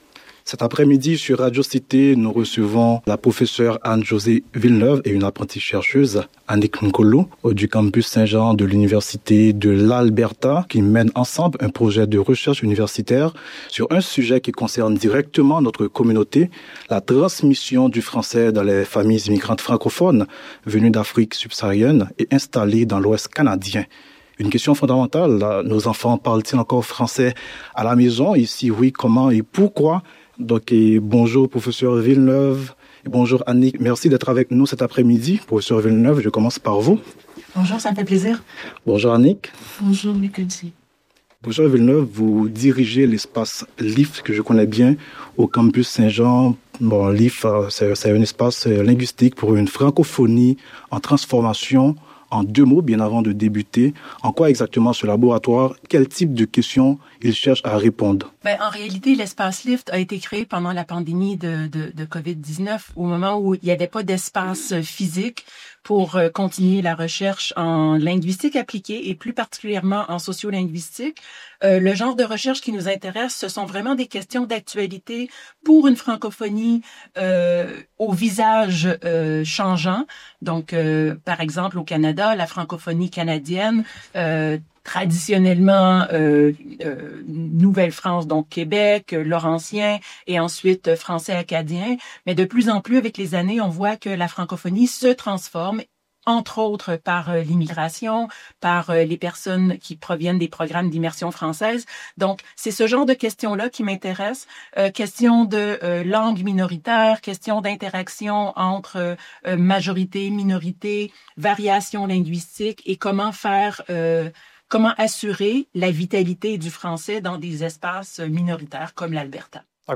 Voici une entrevue